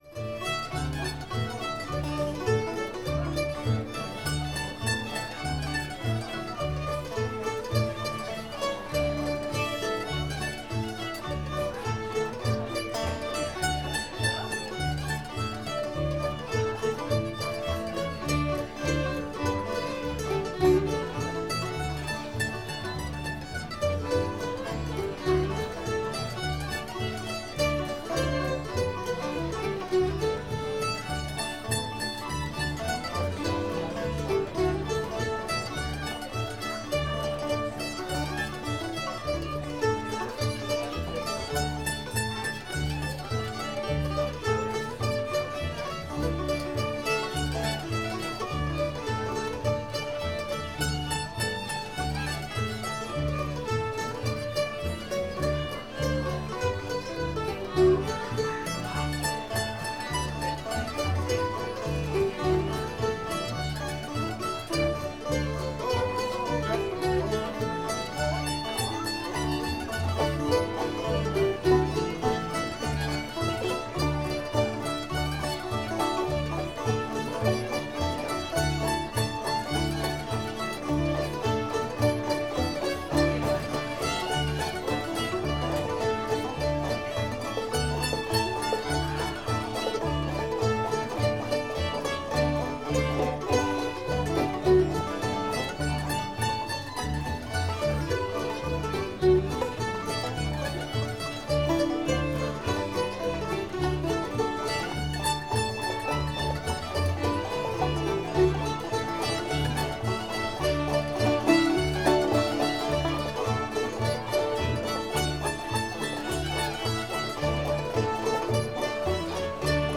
D